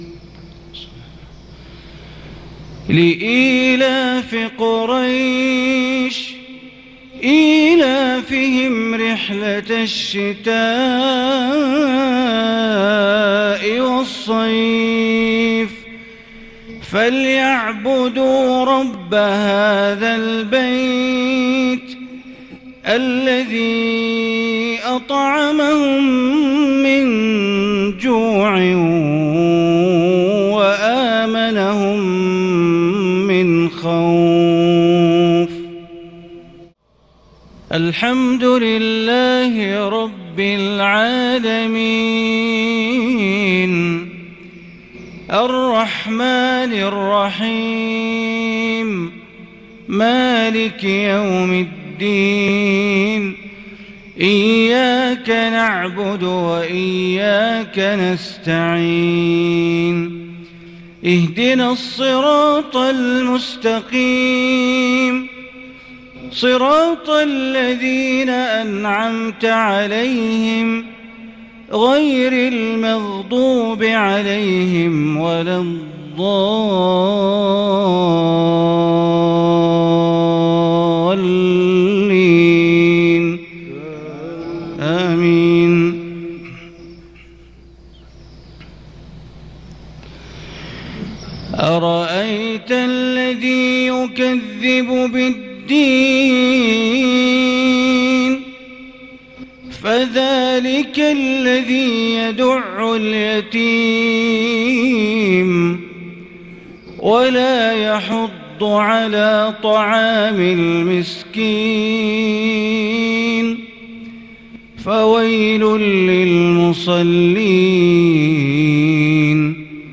صلاة المغرب من سورتي قريش والماعون ٢-٥-١٤٤٢هـ | > 1442 هـ > الفروض - تلاوات بندر بليلة